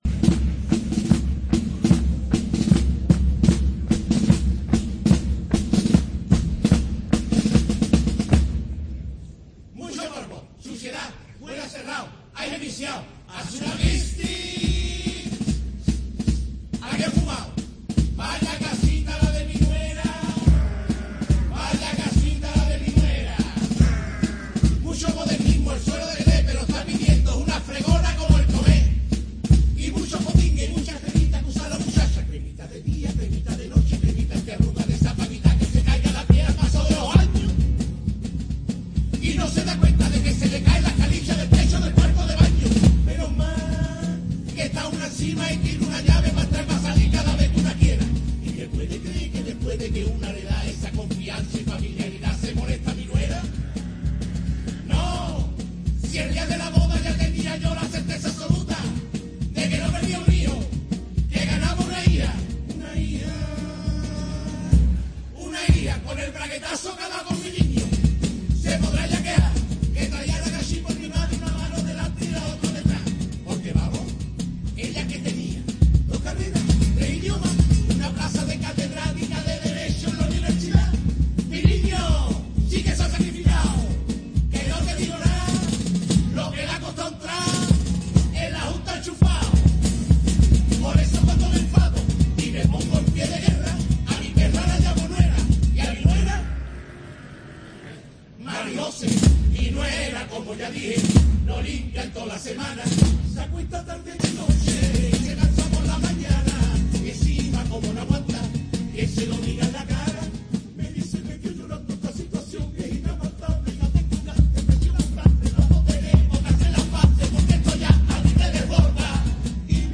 Chirigota "Mi suegra como ya dije"